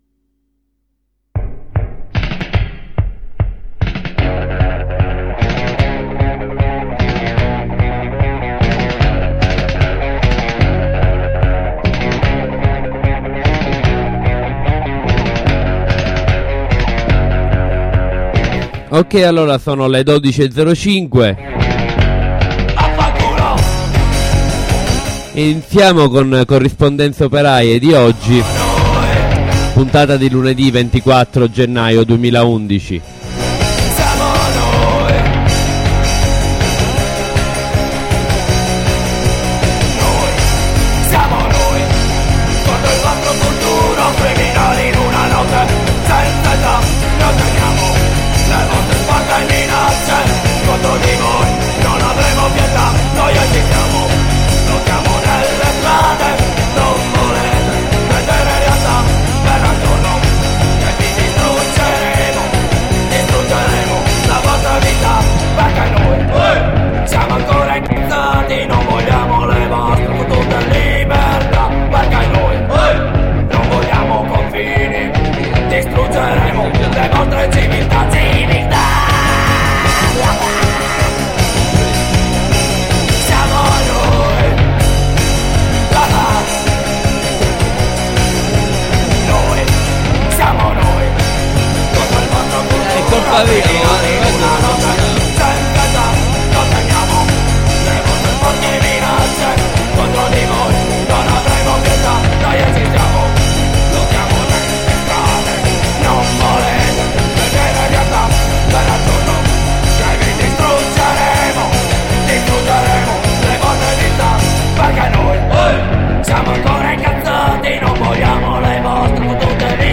53°puntata: presentazione dell'assemblea studenti lavoratori che si terrà il 25 gennaio alle ore 17 presso la facoltà di matematica all'universita la sapienza. in studio i compagni del collettivo inchiesta operaia e uno studente di matematica.